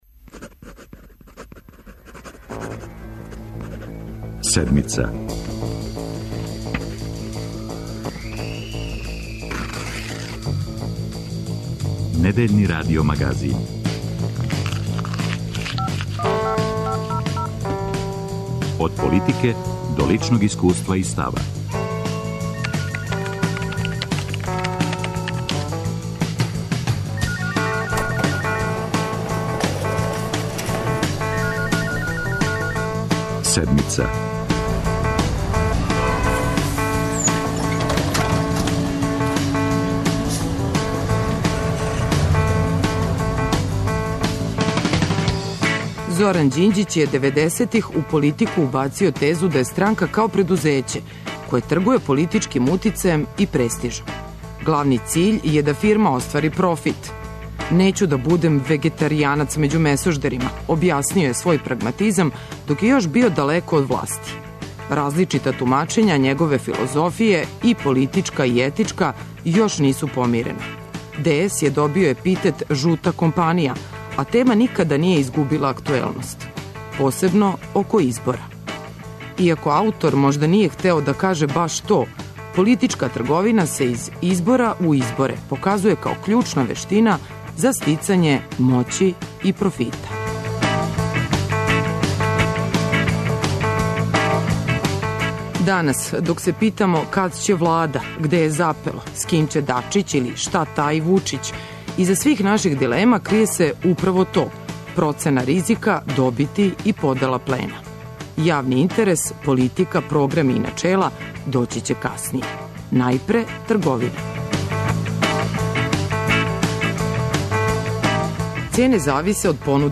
Ко профитира, добија или губи, у недељу говори в.д. председника Српске напредне странке Александар Вучић.